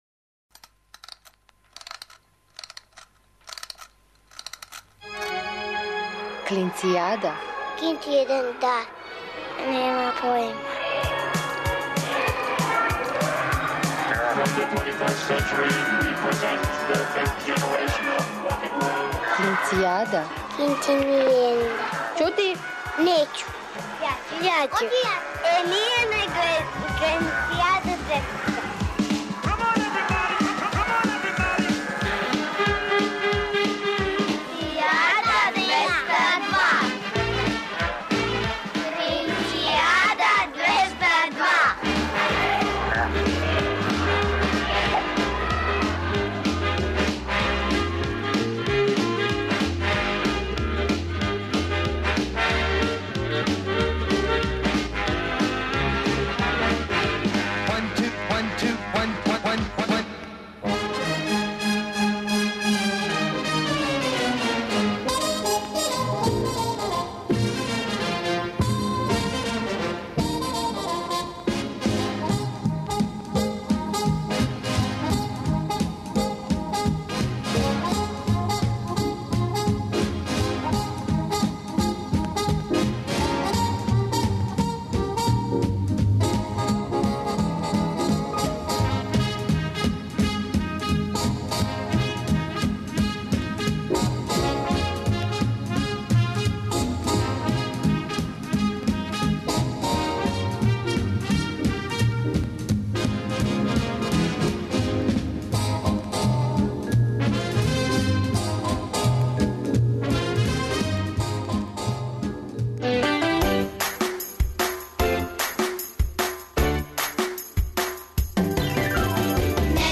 О деци за децу, емисија за клинце и клинцезе, и све оне који су у души остали деца. Сваке недеље уживајте у великим причама малих људи, бајкама, дечјим песмицама.